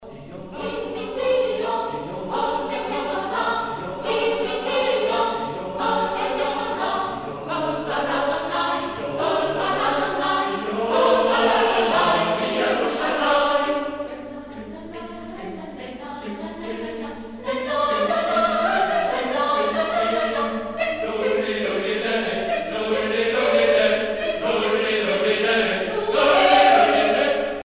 Prières et chants religieux
Pièce musicale éditée